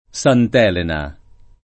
Sant $lena] top.